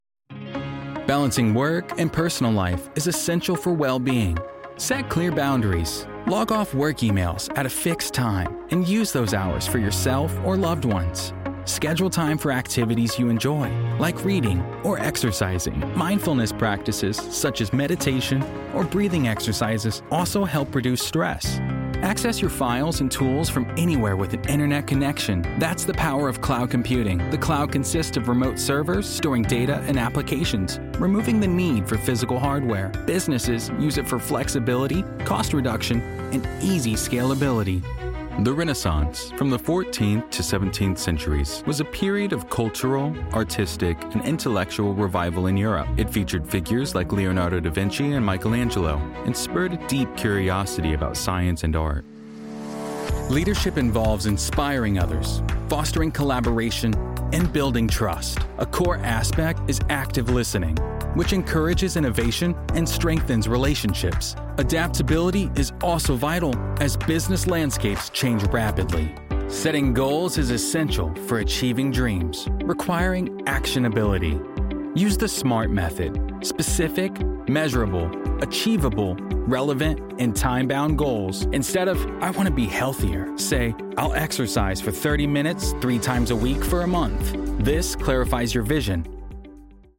For narration, they deliver clear, steady, and emotionally grounded performances that keep the listener engaged.
NARRATION 😎
broadcast level home studio